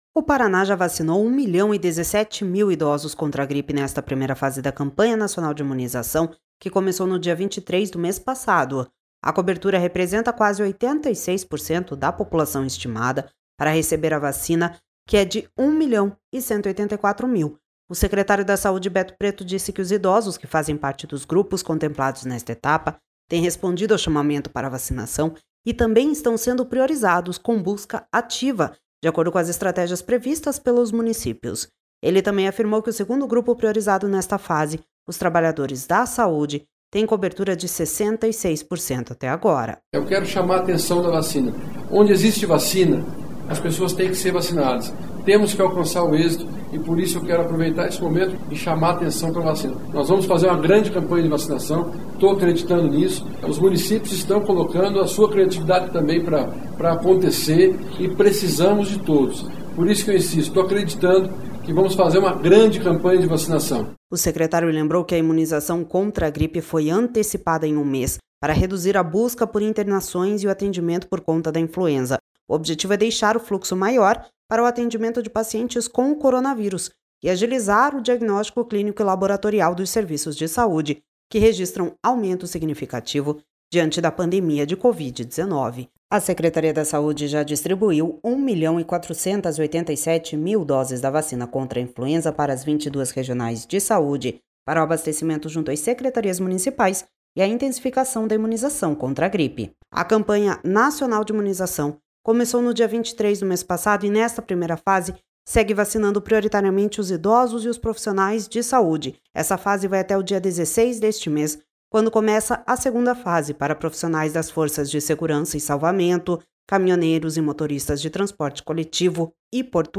Ele também afirmou que o segundo grupo priorizado nesta fase, os trabalhadores da saúde, têm cobertura de 66% até agora.// SONORA BETO PRETO//